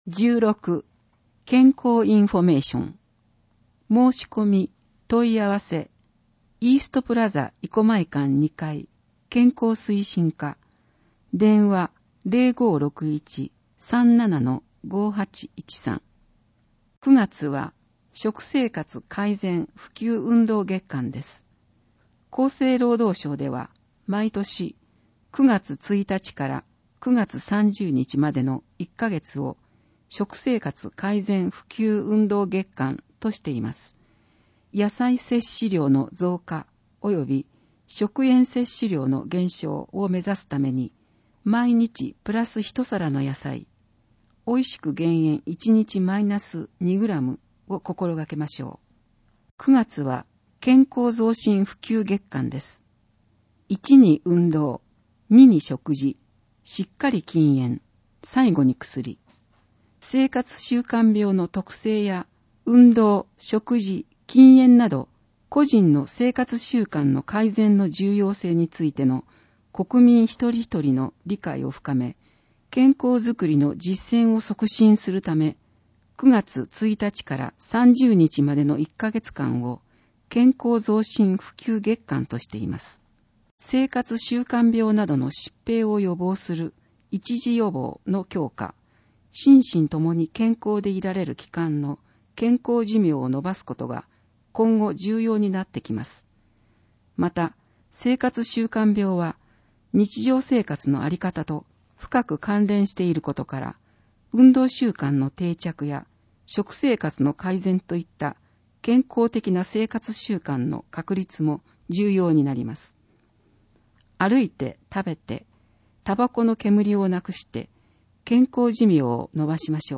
広報とうごう音訳版（2019年9月号）